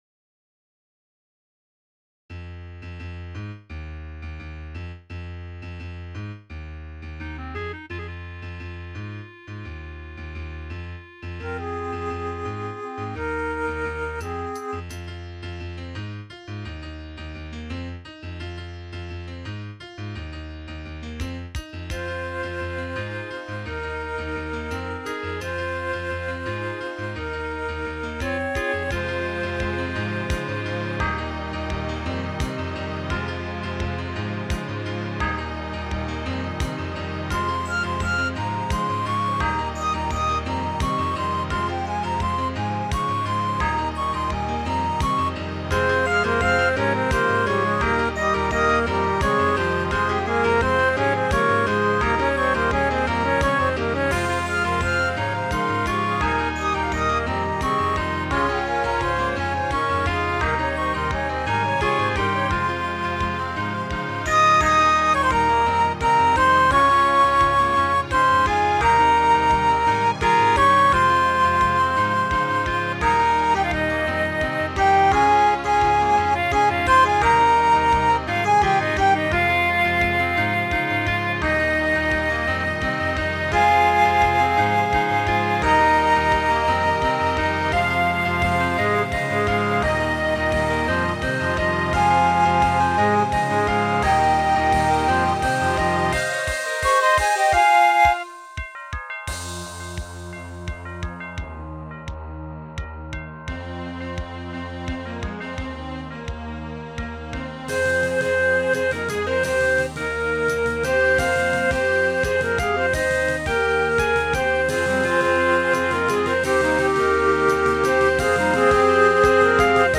Tags: Piano, Clarinet, Woodwinds, Strings, Percussion
Title Red Shoes Opus # 34 Year 2002 Duration 00:03:08 Self-Rating 3 Description This one is just weird, I know, but I've come to really like it. mp3 download wav download Files: mp3 wav Tags: Piano, Clarinet, Woodwinds, Strings, Percussion Plays: 1976 Likes: 0